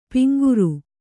♪ piŋguru